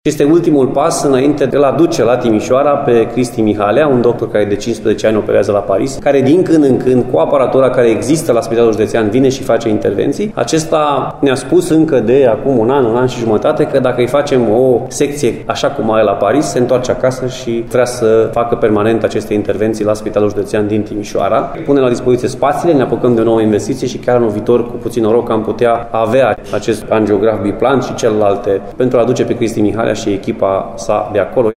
Potrivit președintelui Consiliului Județean Timiș, Alfred Simonis, un medic român specializat în Franța va realiza la Timișoara astfel de proceduri.